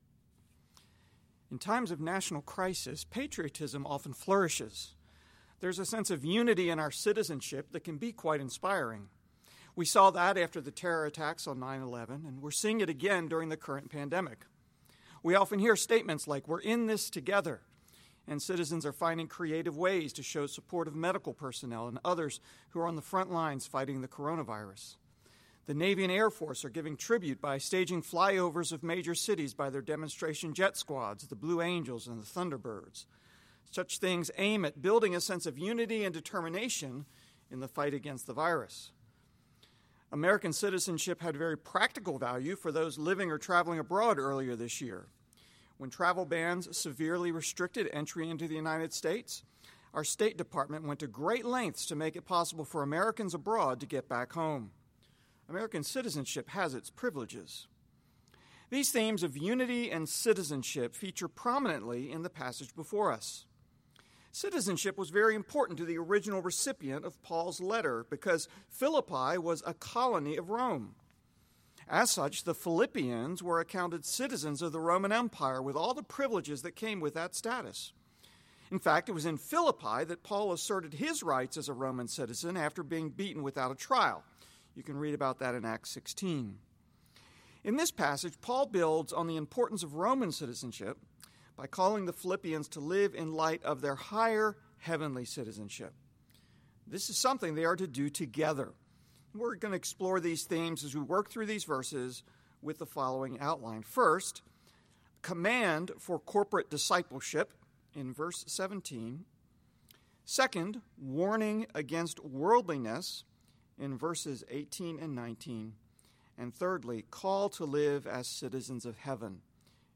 Paul’s call for Philippian Christians to live out their heavenly citizenship can be source of hope for us in difficult times. This message was recorded for at-home worship.